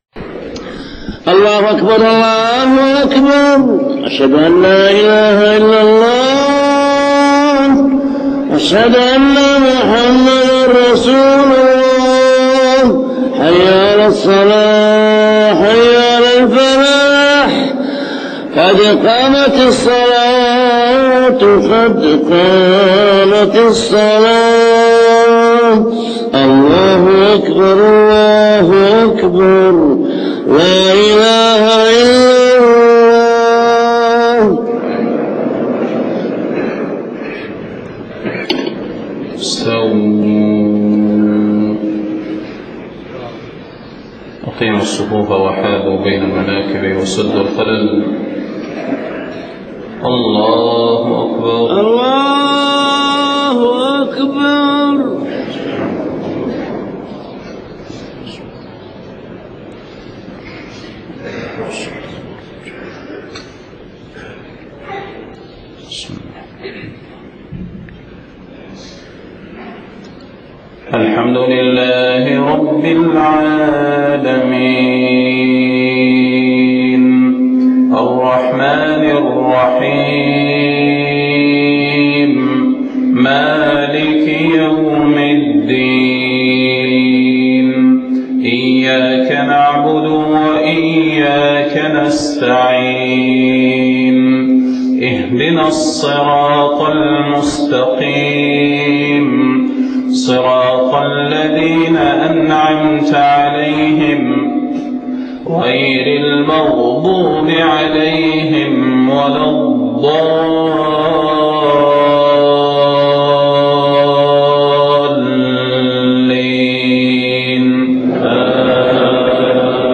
صلاة الفجر 22 محرم 1430هـ من سورة آل عمران 100-112 > 1430 🕌 > الفروض - تلاوات الحرمين